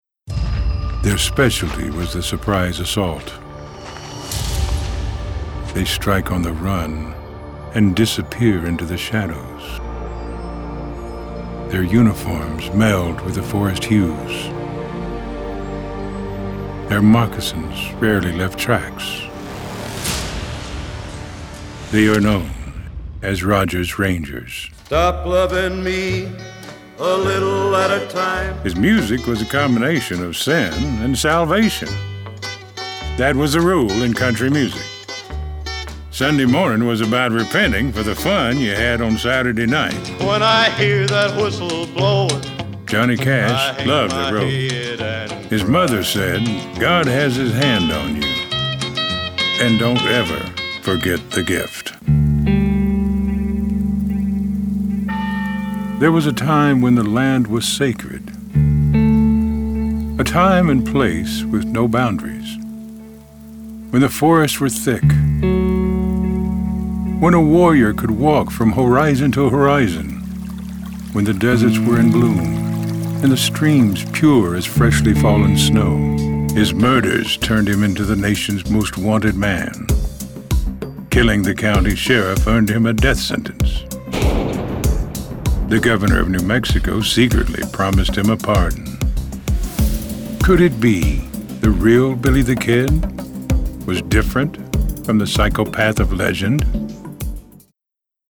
A little grit, a deep, warm, engaging read with a little bit of Texas thrown in for good measure.
produced at Radio Lounge: